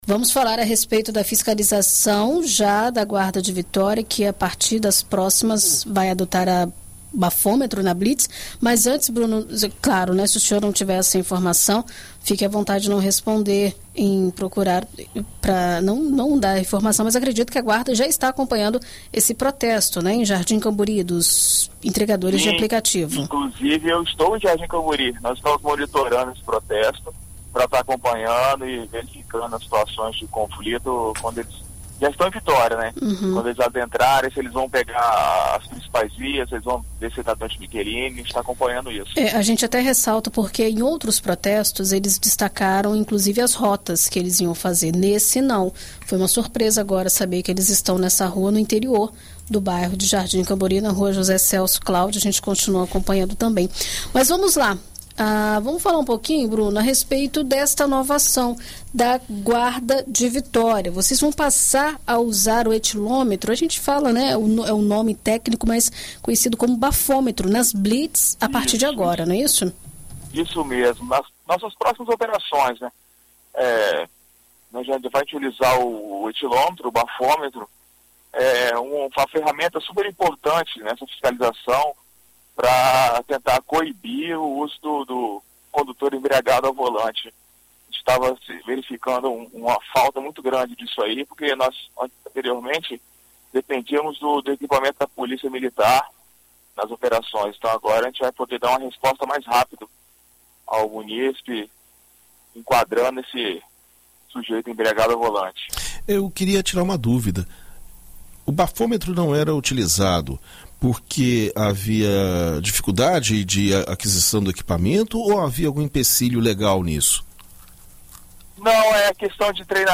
Em entrevista à BandNews FM Espírito Santo nesta sexta-feira (26)